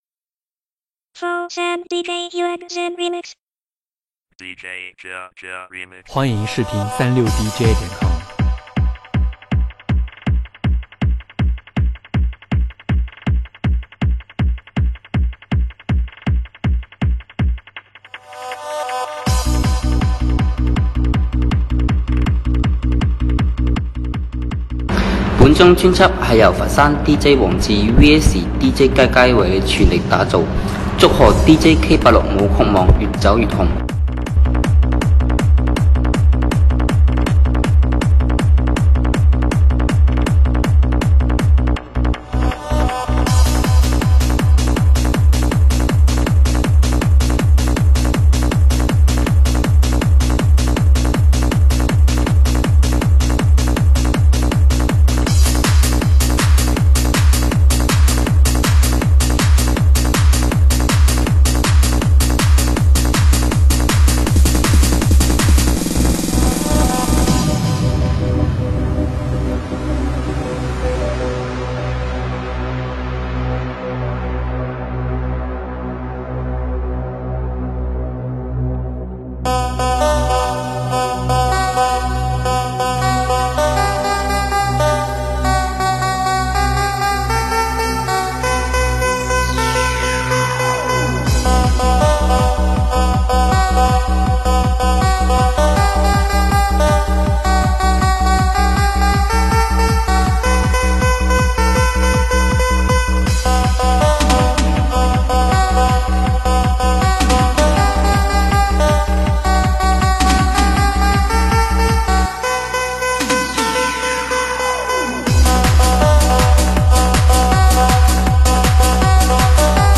栏目： 现场串烧